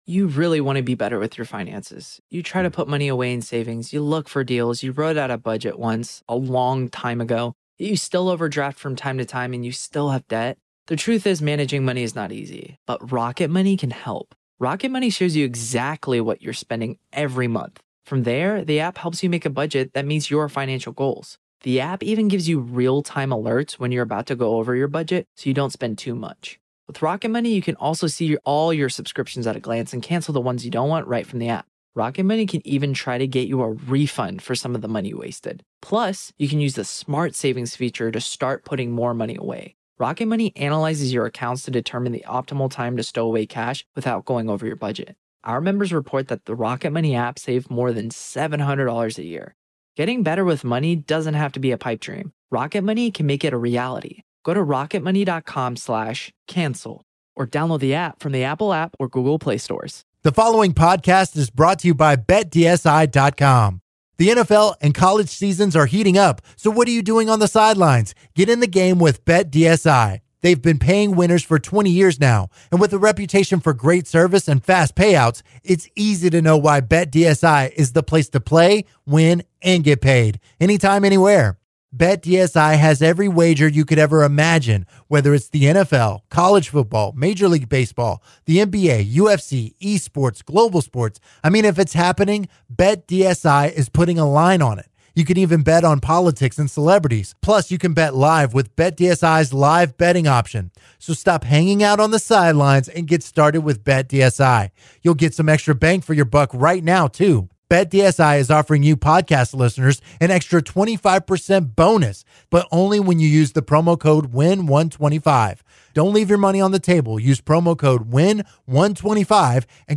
He also gives his take on the offensive teams in the NFL. The guys take calls from listeners who have to work the day before Thanksgiving.